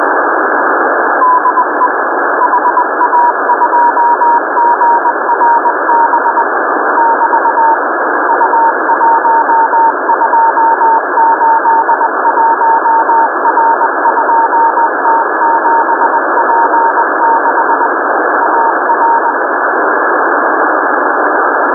Modulation : GMSK, CW G3RUH 9k6 baud (UHF, VHF)
Morse: 20 WPM